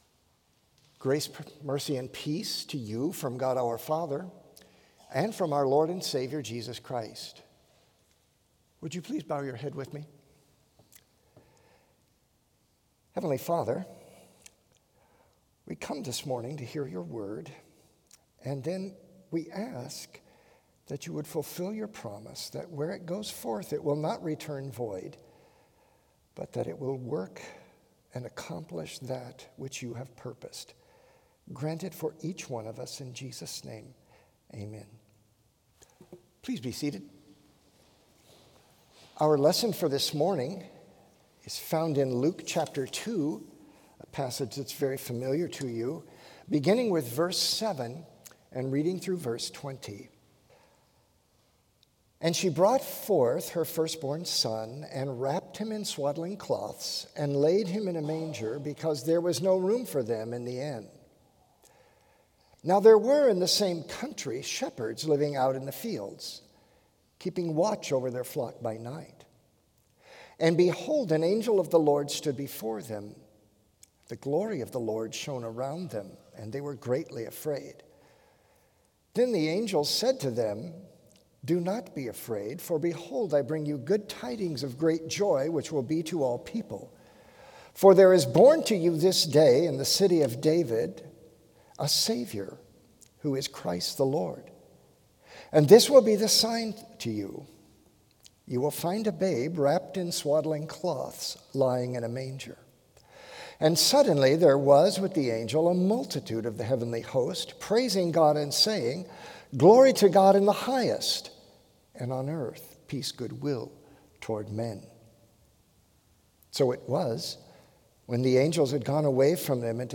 Complete service audio for Chapel - Thursday, December 11, 2025